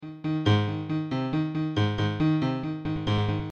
骑行电吉他
标签： 69 bpm Rap Loops Guitar Electric Loops 599.23 KB wav Key : Unknown
声道立体声